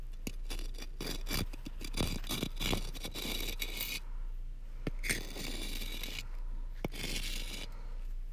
Stone scraping.wav